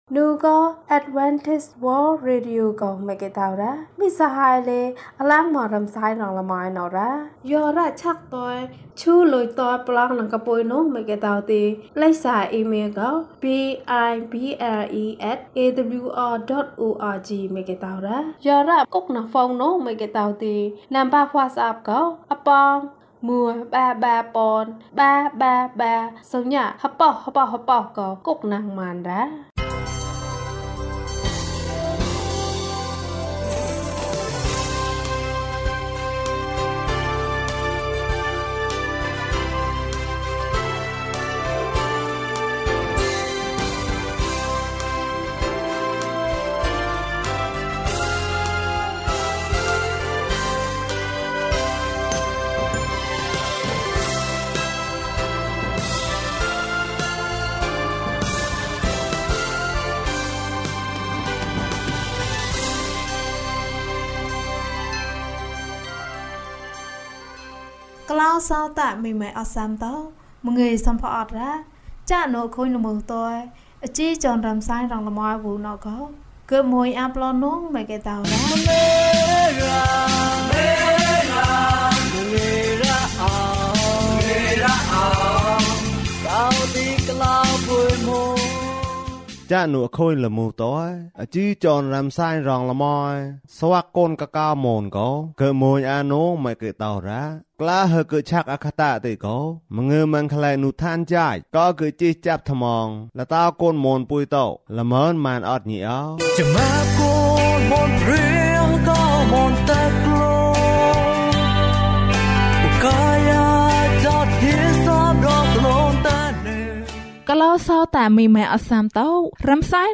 ဘုရားသခင်သည် ချစ်ခြင်းမေတ္တာဖြစ်သည်။၀၇ ကျန်းမာခြင်းအကြောင်းအရာ။ ဓမ္မသီချင်း။ တရားဒေသနာ။